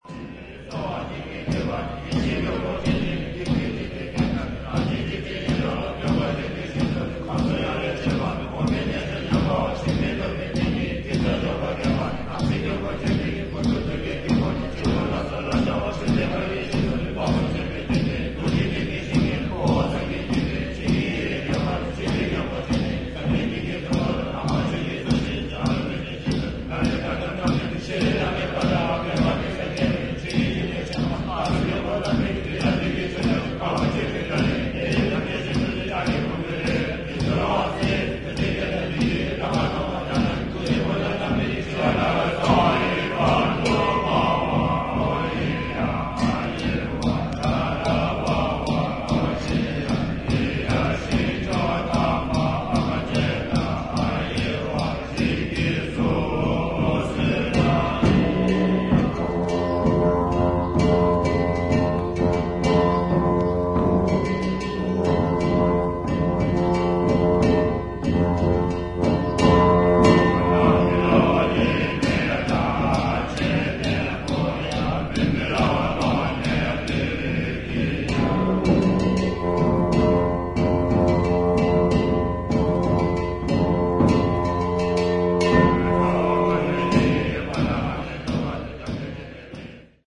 本作は、ブータンの伝統的な宗派「ドゥプカ派」の儀式に焦点を当て、総勢76人のラマ僧と僧侶が神聖な寺院で長いトランペット、ショーム、シンバル、太鼓などのチベット楽器を用いて詠唱し、演奏。臨場感あふれる声明や打楽器の演奏、メディテーティブな僧院音楽が生々しく記録された大変貴重な音源です。